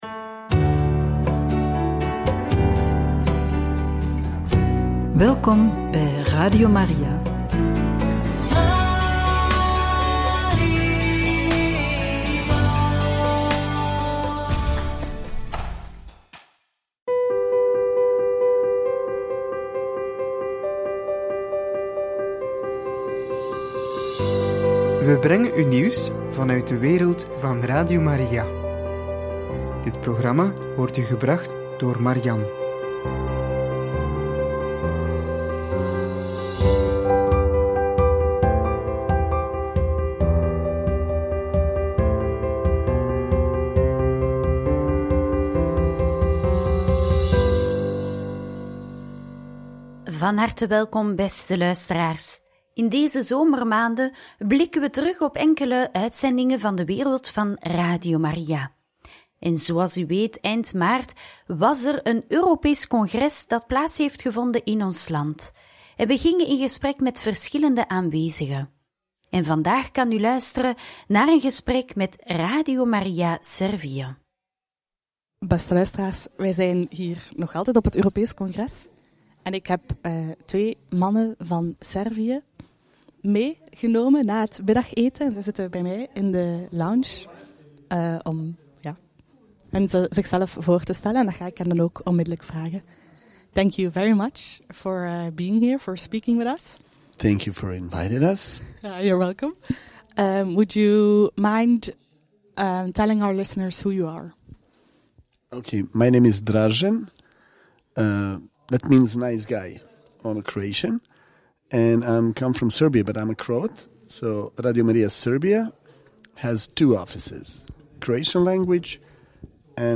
In gesprek met de delegatie van Radio Maria Slovakije en Radio Maria Kroatië – Radio Maria